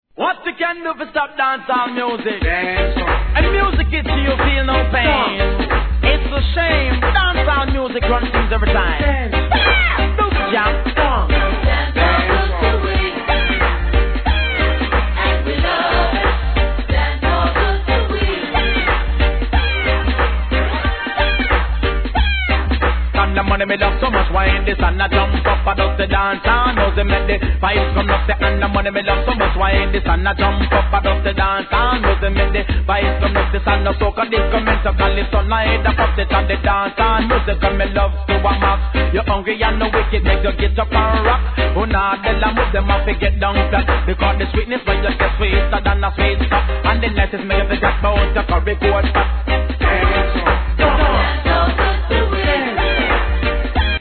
80年代後半のデジタル・ダンスホール期重要レーベル